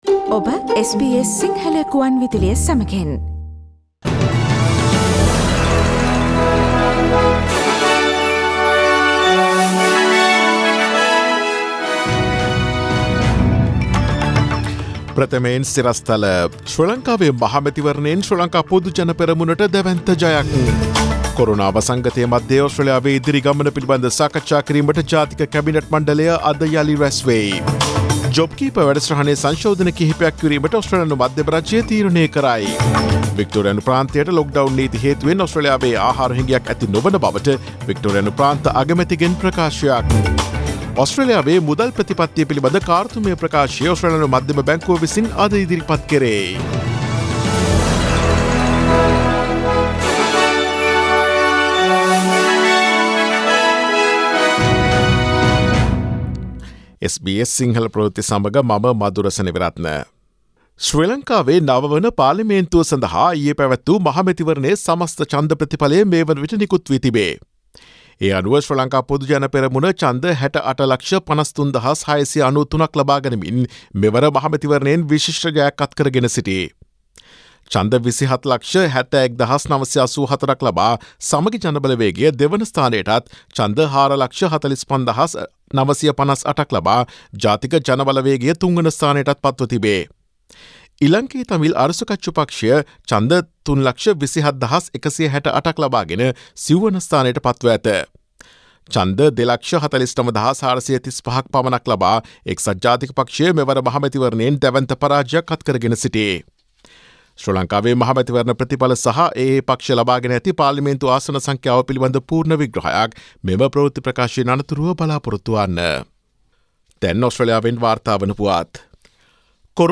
Daily News bulletin of SBS Sinhala Service: Friday 07 August 2020